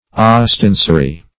Search Result for " ostensory" : The Collaborative International Dictionary of English v.0.48: Ostensorium \Os`ten*so"ri*um\, Ostensory \Os*ten"so*ry\, n.; pl.